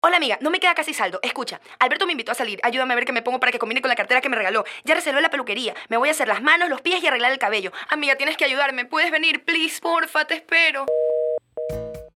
female | South American | Commercial | adult